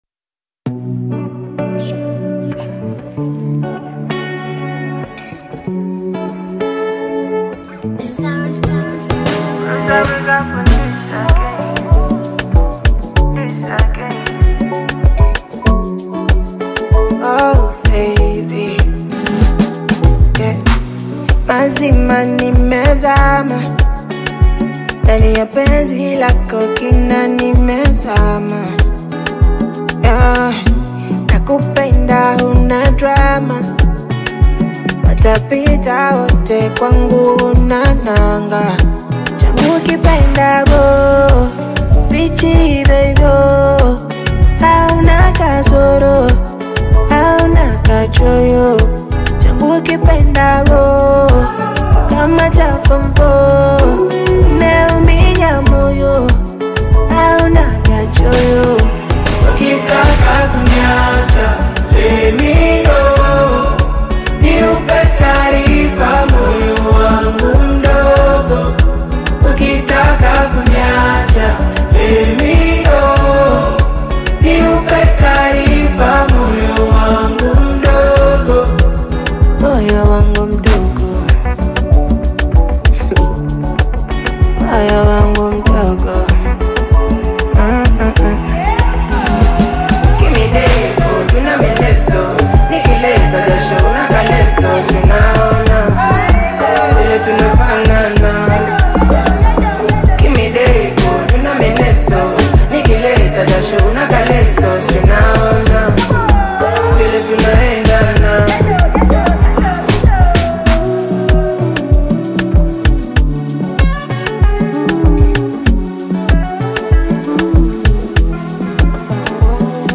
Bongo Flava music track
Tanzanian Bongo Flava